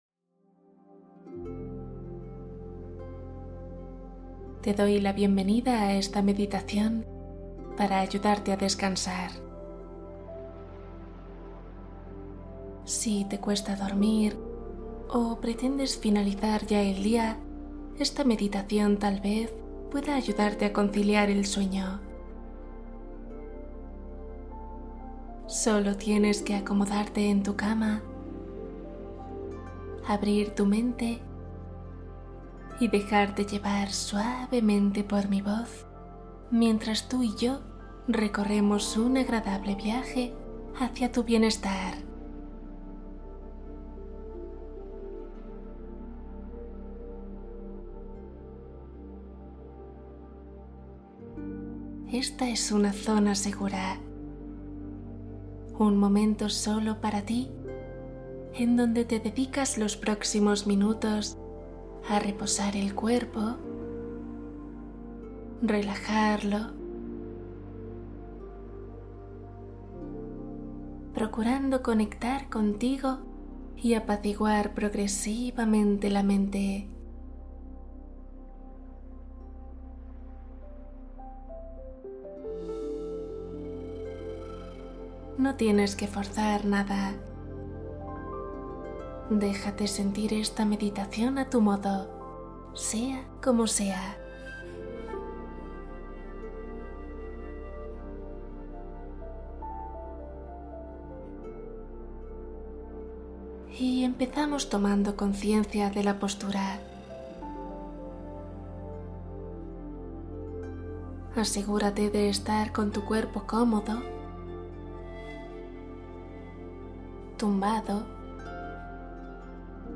Meditación diaria 10 minutos para iniciar el día con claridad